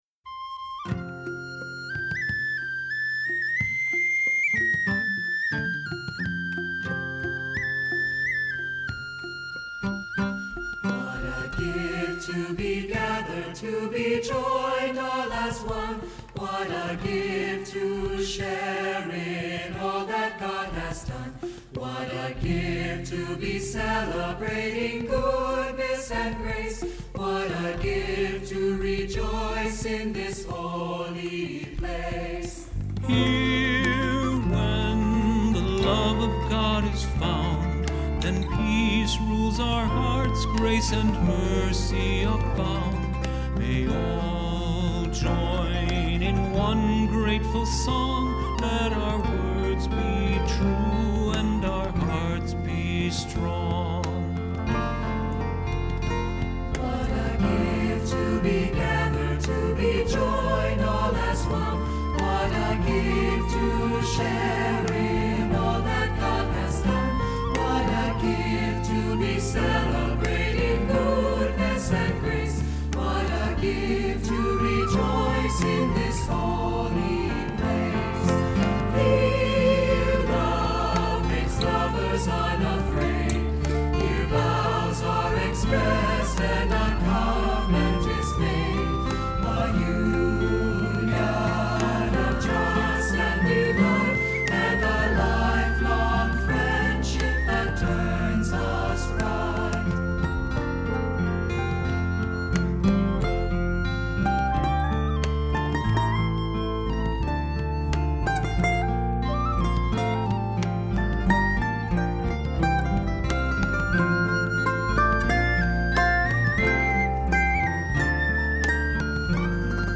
Voicing: Two-part mixed; Assembly